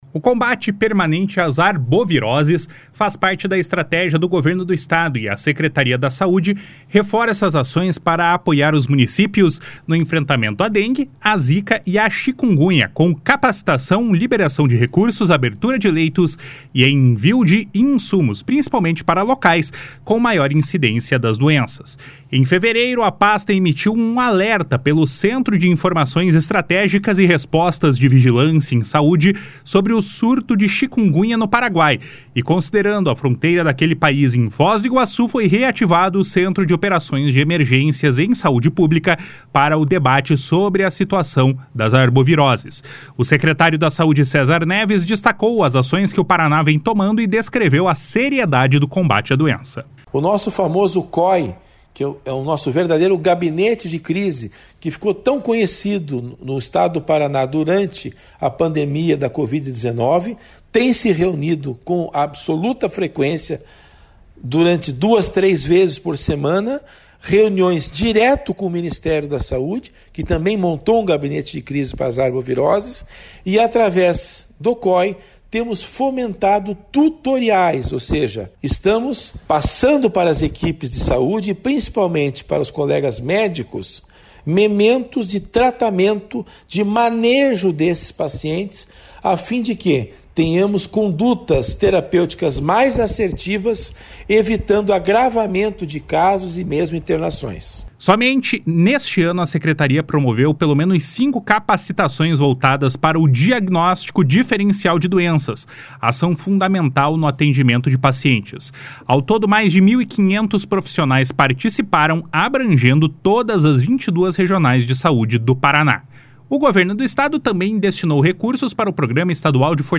O secretário da Saúde, César Neves, destacou as ações que o Paraná vem tomando e descreveu a seriedade do combate a doença.